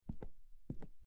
Footsteps On Wood Floor 03
Footsteps_on_wood_floor_03.mp3